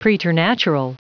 Prononciation du mot preternatural en anglais (fichier audio)
Prononciation du mot : preternatural